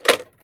gear_rattle_weap_launcher_07.ogg